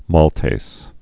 (môltās, -tāz)